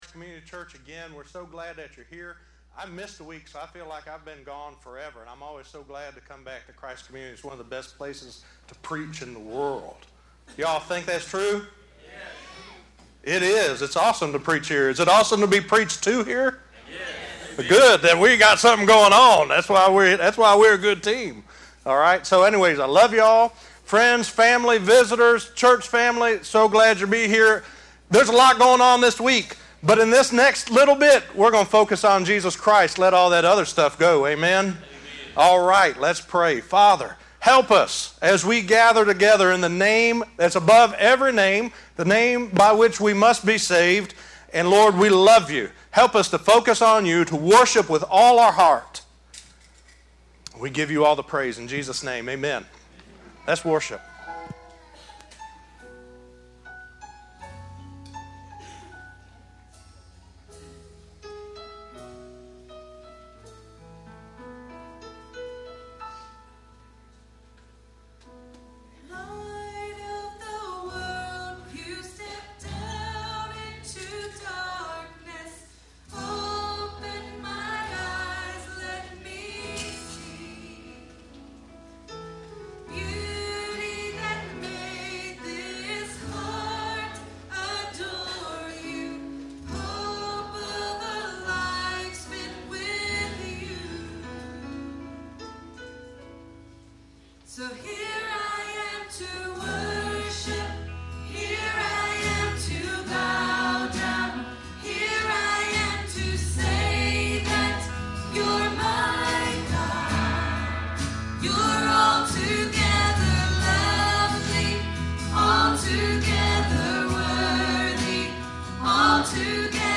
The Gospel of Jesus Christ the Son of God 2 - Messages from Christ Community Church.